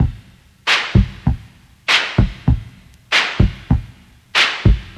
• 97 Bpm HQ Drum Loop D Key.wav
Free breakbeat - kick tuned to the D note. Loudest frequency: 1520Hz
97-bpm-hq-drum-loop-d-key-at9.wav